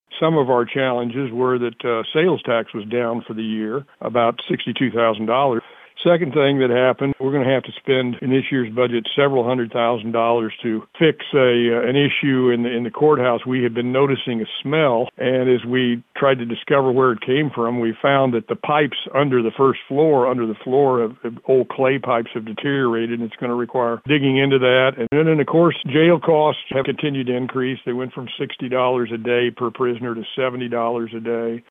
Douglas explained they have some challenges in the coming year.